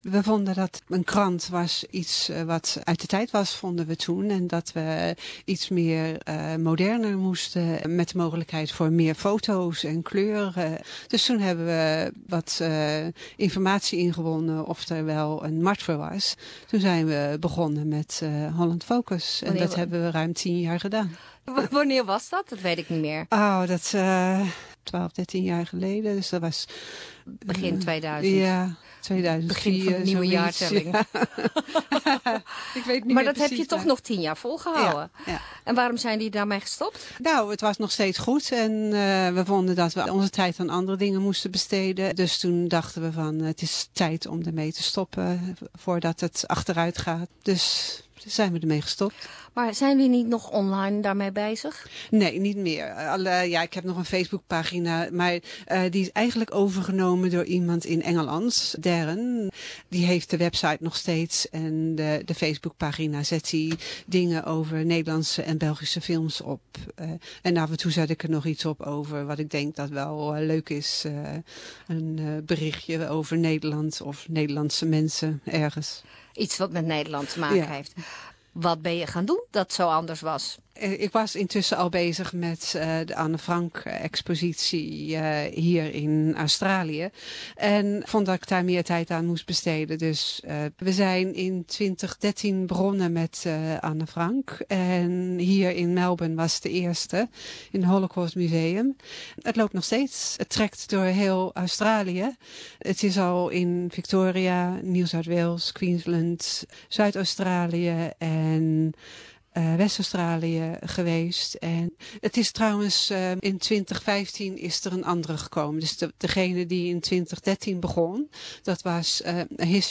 Studio guest